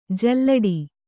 ♪ jallaḍi